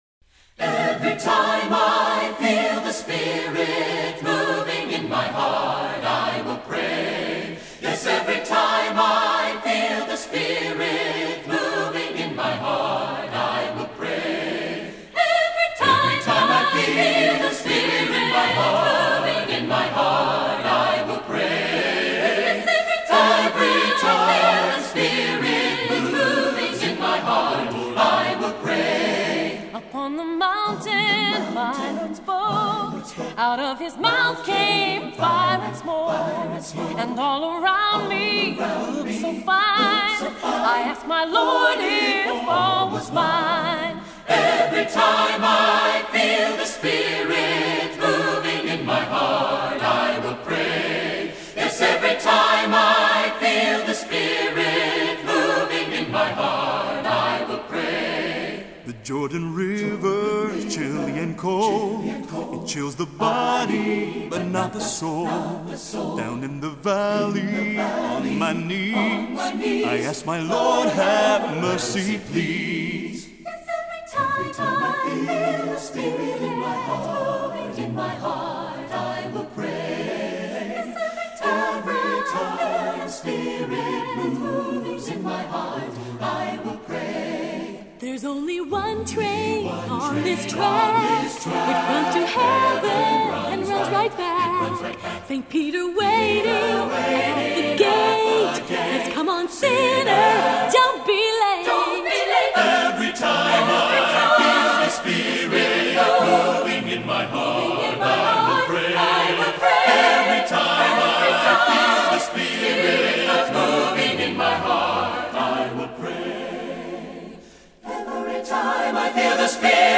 Composer: Spiritual
Voicing: SATB a cappella